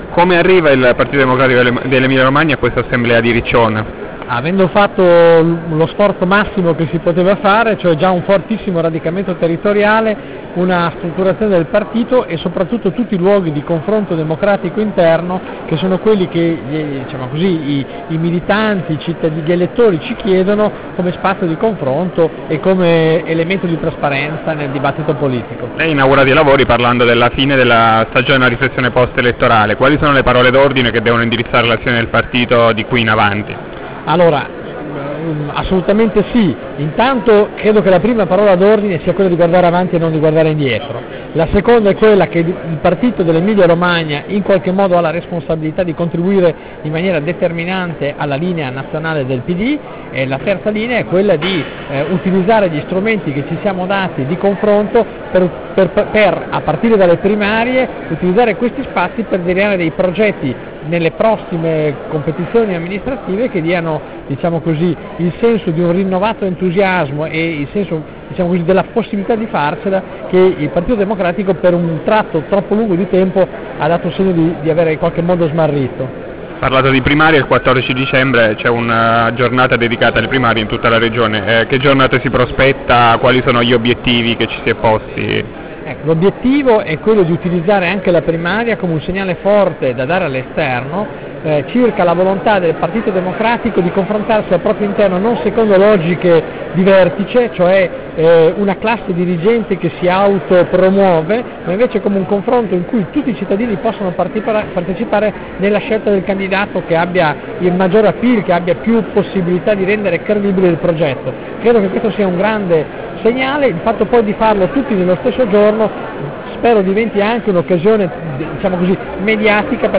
Intervista a Tiziano Tagliani in formato audio